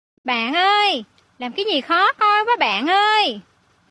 Âm thanh meme Bạn ơi, làm cái gì khó coi quá bạn ơi…
Thể loại: Câu nói Viral Việt Nam
Âm thanh ngắn, dễ nhớ, phù hợp để edit clip trên mạng xã hội, tạo meme, những khoảnh khắc hài hước, khiến nội dung trở nên sinh động và hấp dẫn.